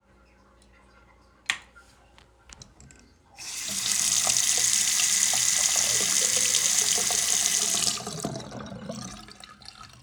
273-small-sink-recording-online-audio-converter.com_.mp3